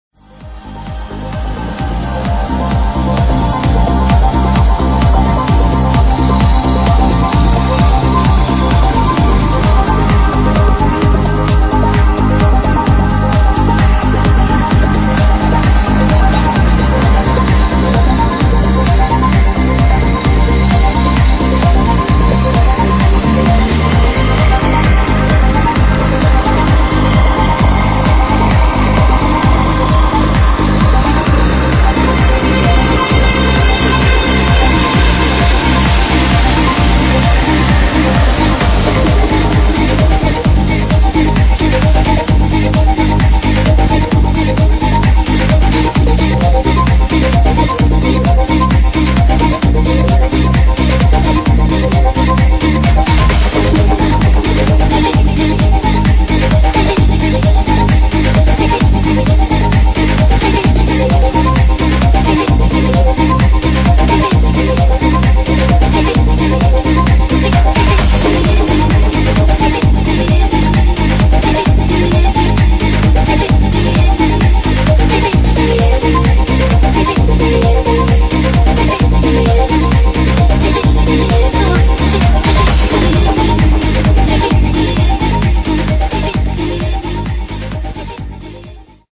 Amazing Melodic Tune